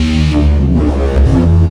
Bass 1 Shots (105).wav